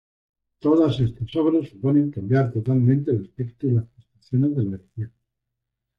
/asˈpeɡto/